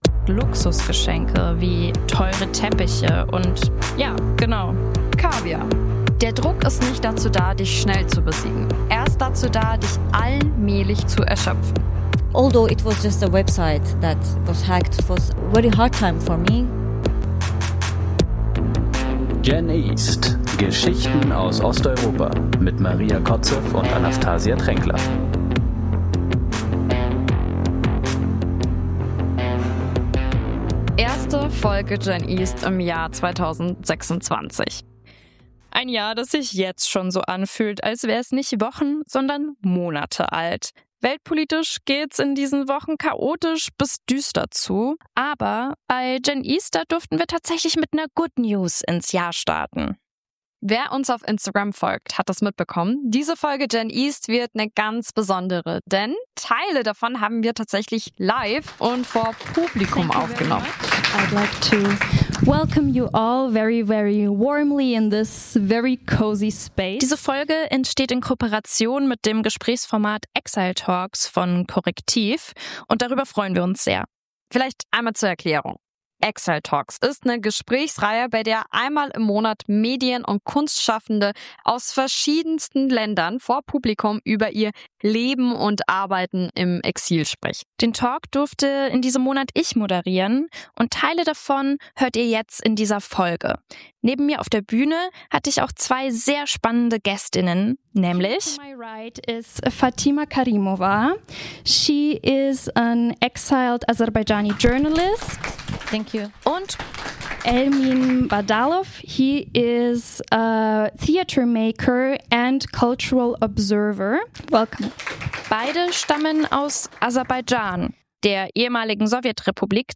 Teile davon haben wir nämlich live vor Publikum aufgenommen!